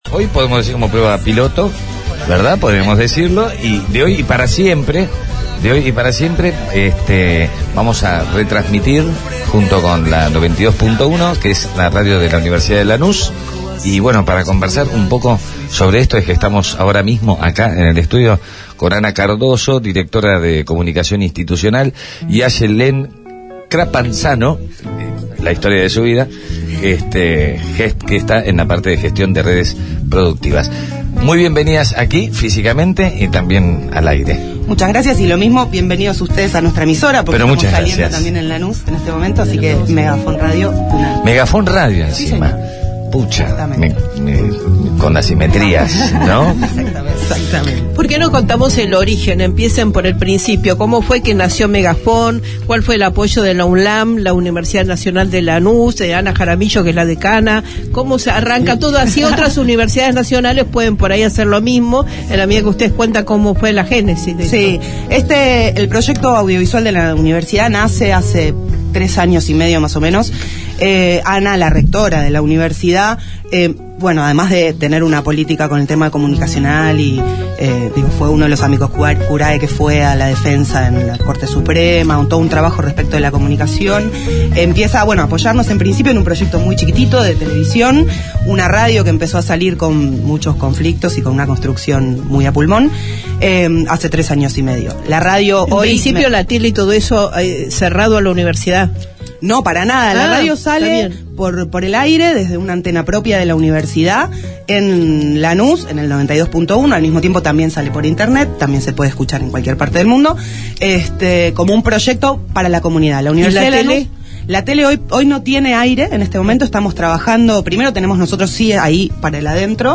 ENTREVISTA-UNLA.mp3